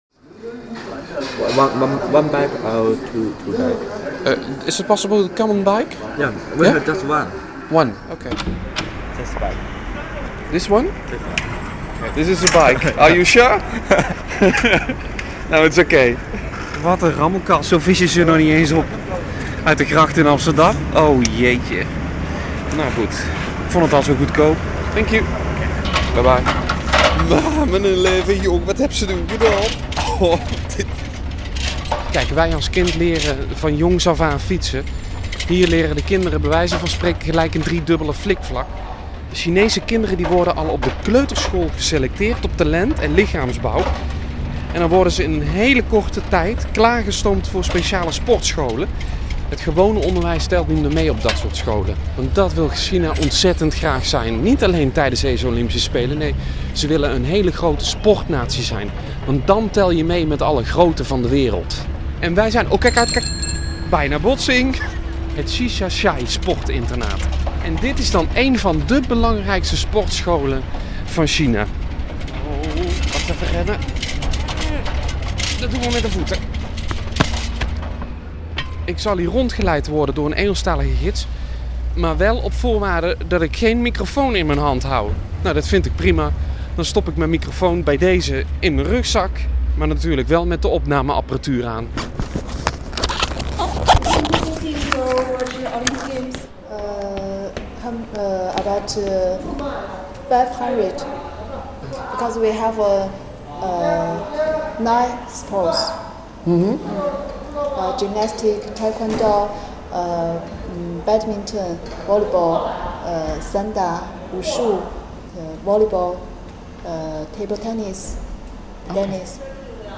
reportage Olympische Spelen
repo-Spelen-kindersportinternaat.mp3